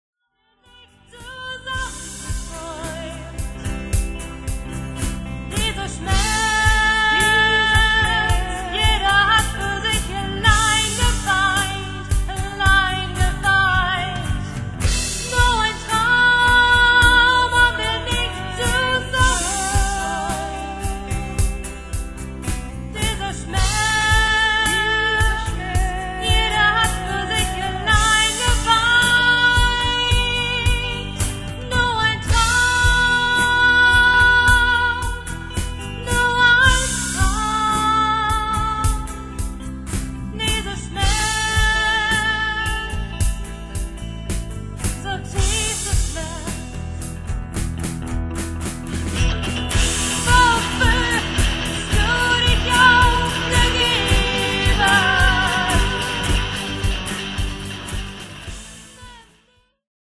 Demo Songs